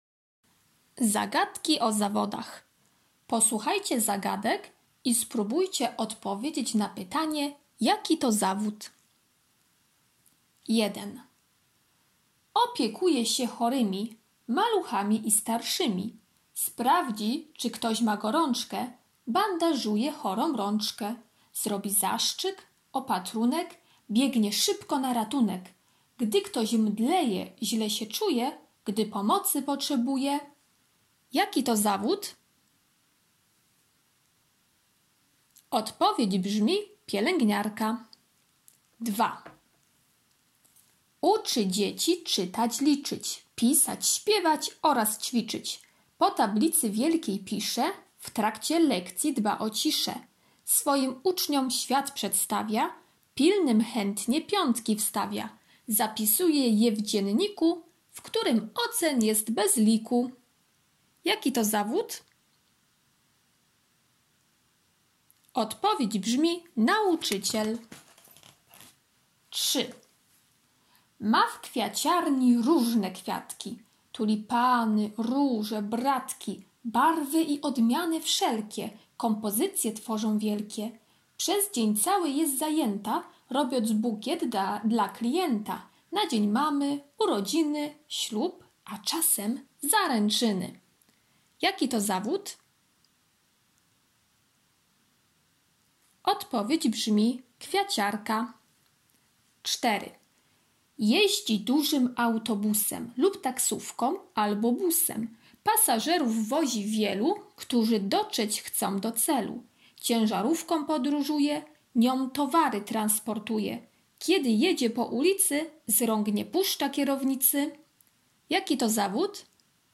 piątek - zagadki cz. 1 [9.02 MB]